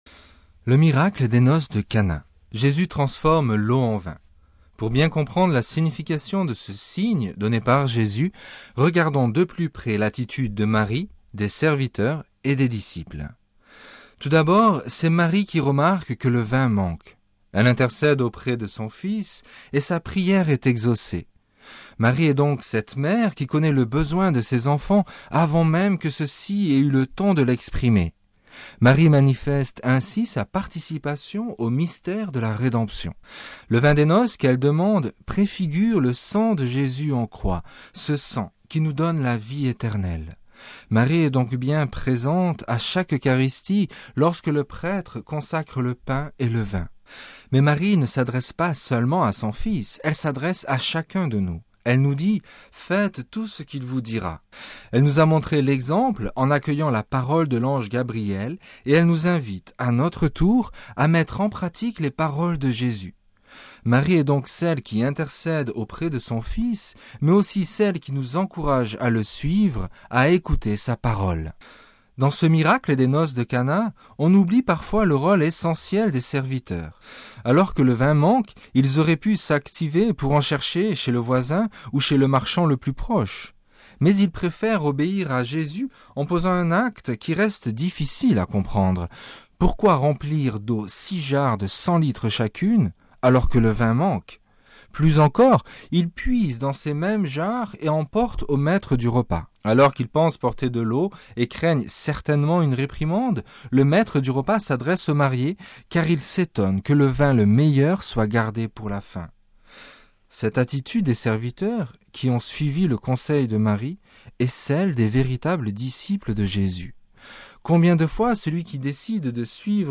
Le commentaire de l'Evangile du dimanche 20 janvier